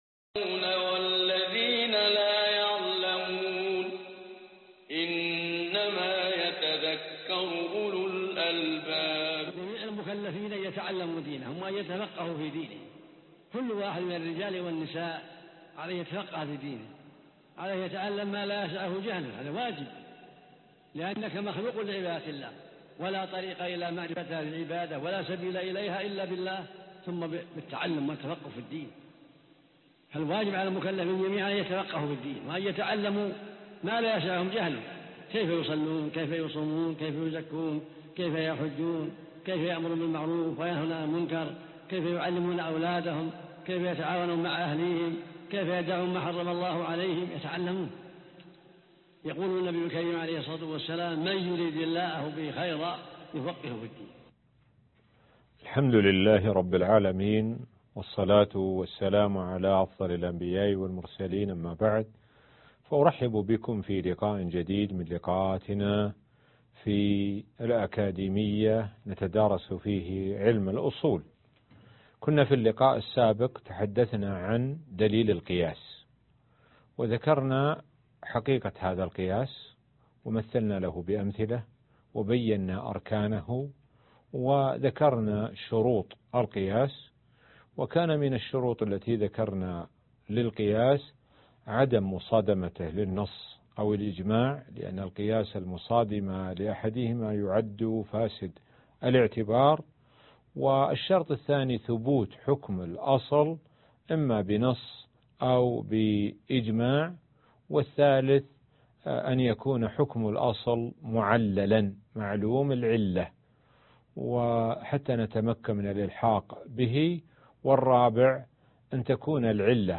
الدرس 10 ( الأصول من علم الأصول - البناء العلمي ) - الشيخ سعد بن ناصر الشثري